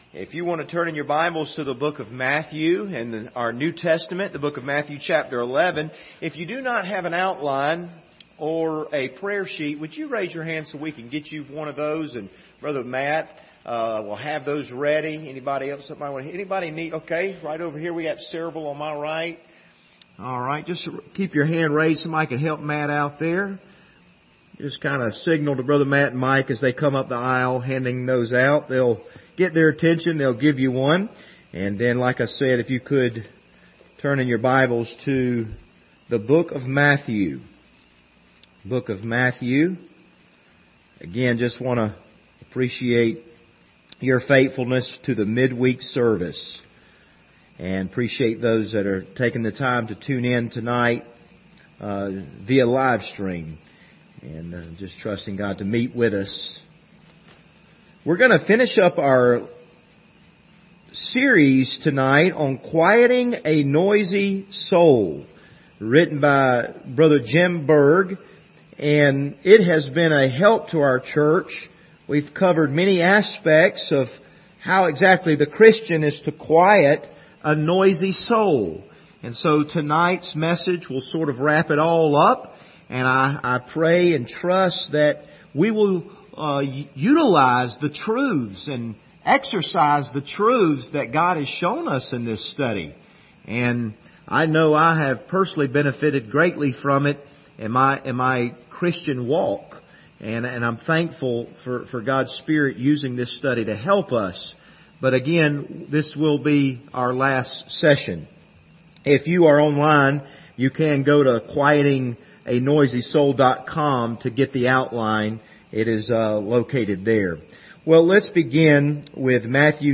Passage: Matthew 11:28-30 Service Type: Wednesday Evening